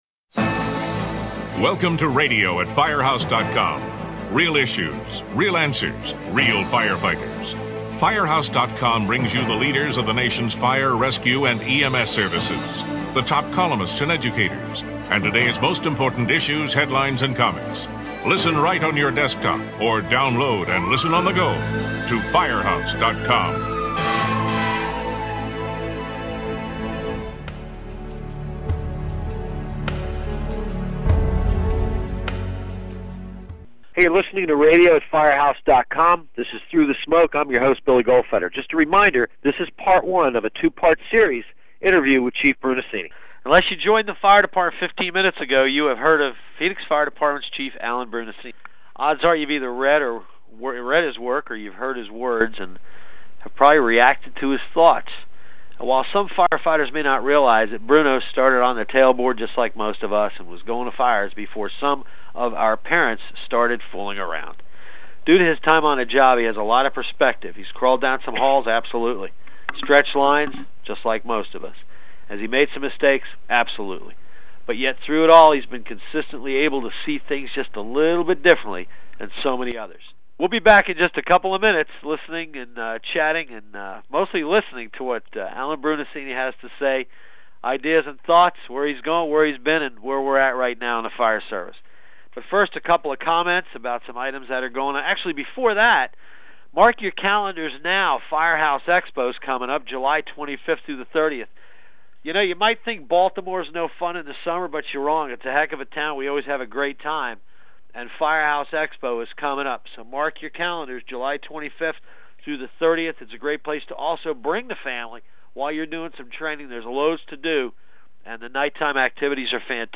Through the Smoke: Chat with Chief Alan Brunacini - Part 1